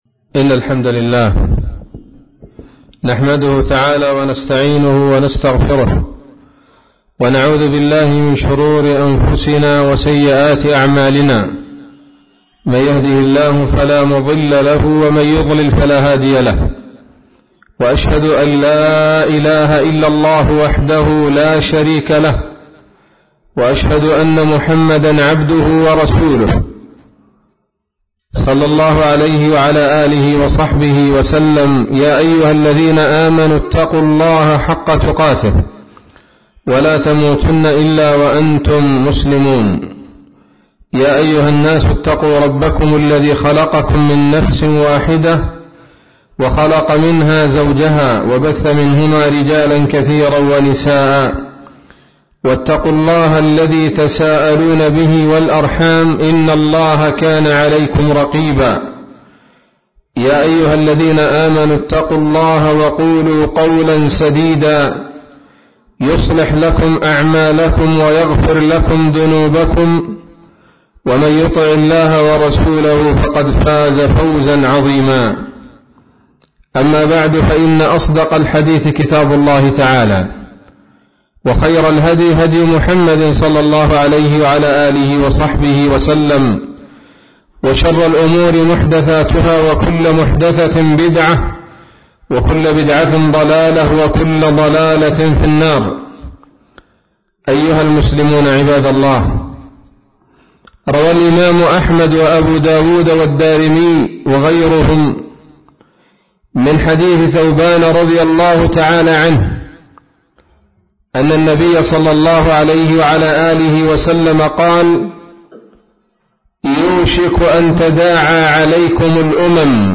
خطبة جمعة بعنوان: (( الرجوع إلى الإسلام والحذر من مؤامرات الأعداء )) 15 من شهر جماد الأولى 1441 هـ، مسجد الرحمن -وادي حطيب - يافع